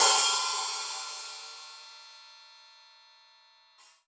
Index of /musicradar/Hi Hats/Sabian B8
CYCdh_Sab_OpHat-09.wav